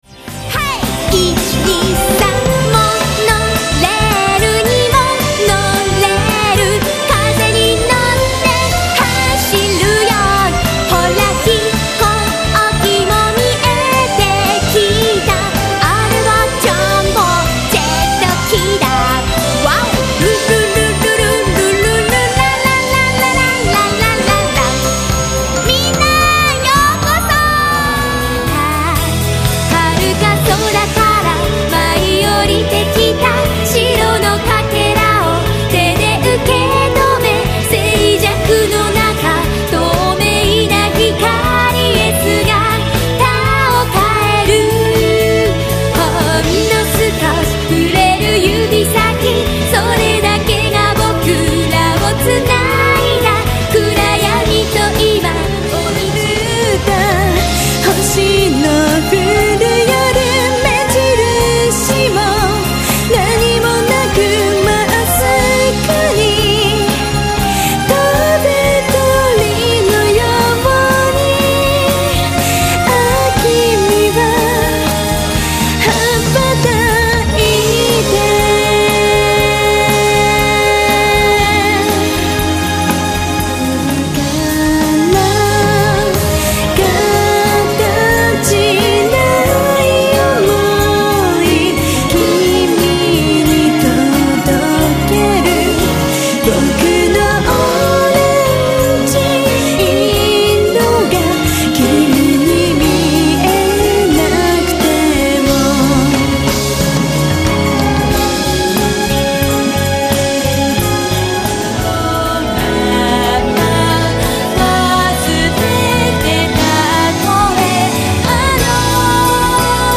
ライブの興奮をそのままＣＤにパッケージしてお届けします。
■全曲クロスフェード　⇒